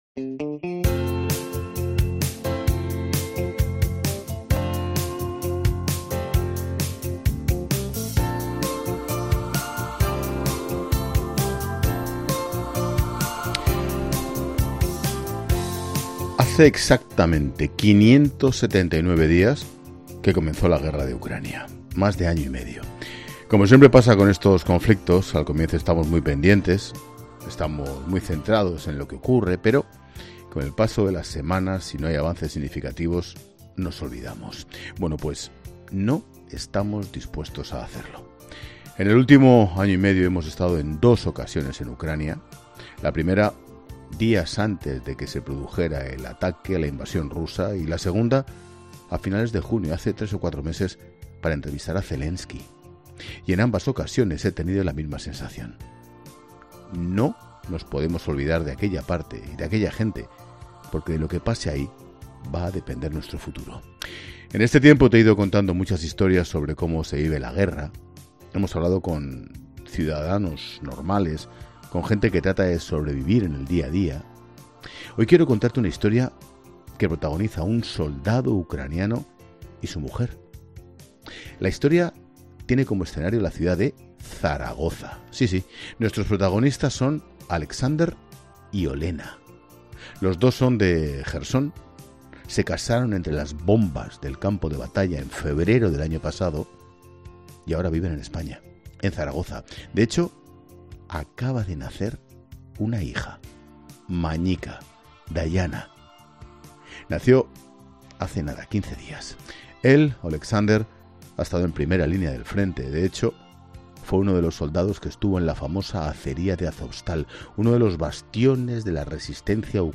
Ángel Expósito acude al Hospital Militar de Zaragoza donde se acoge, desde el comienzo de la invasión rusa, a soldados ucranianos heridos en la guerra